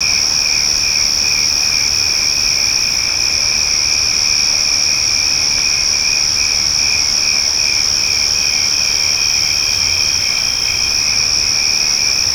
Index of /90_sSampleCDs/E-MU Producer Series Vol. 3 – Hollywood Sound Effects/Ambient Sounds/Night Ambience
NIGHT AMB00R.wav